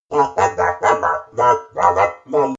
Skel_COG_VO_murmur.ogg